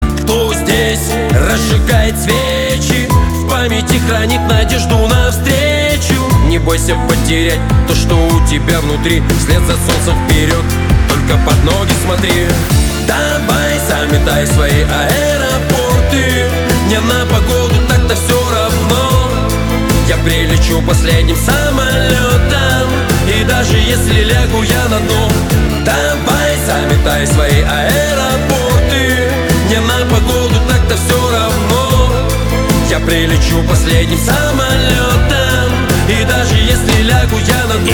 • Качество: 320, Stereo
мужской вокал
душевные
русский рэп